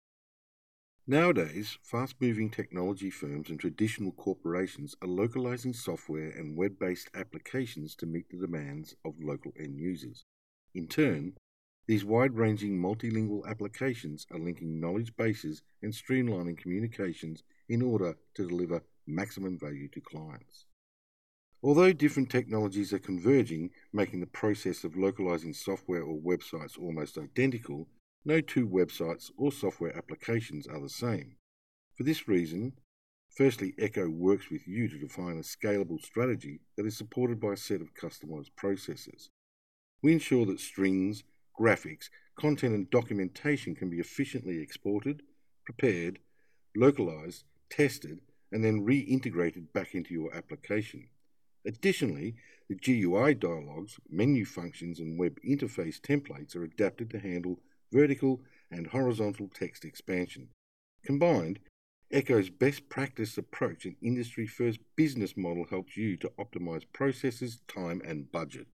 EN (AUS) Male 02265
NARRATION